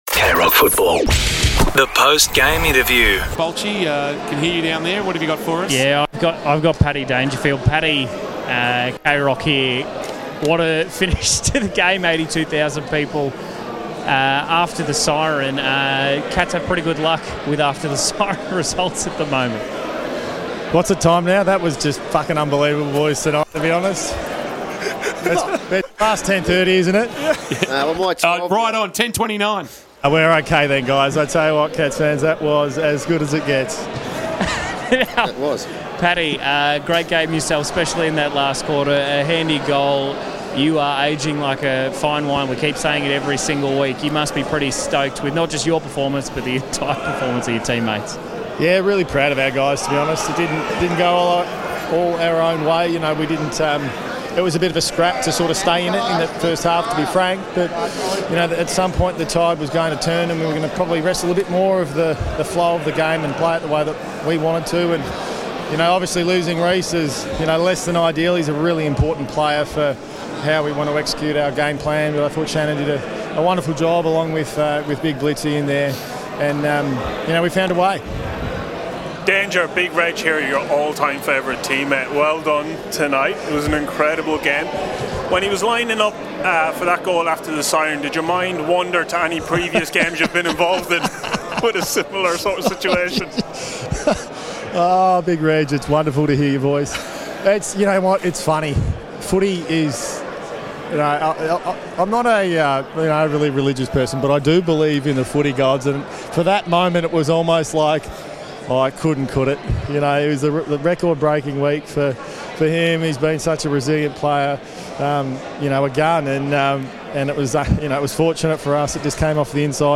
2025 - AFL - Round 8 - Collingwood vs. Geelong: Post-match interview - Patrick Dangerfield (Geelong Cats)